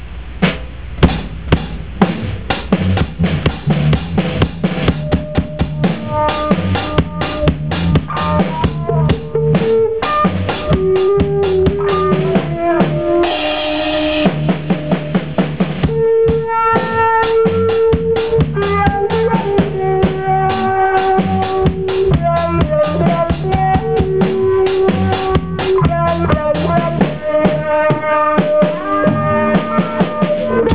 I placed the microphone here and there until I was pleased with the way the drums sounded on tape.
I brought out my bass and played on top of the drums. I brought out my guitar and played over the other two instruments. I redid the guitar and decided to use both attempts.
I used my walkman to help digitize the sound. The big file helped maintain what little sound quality remained.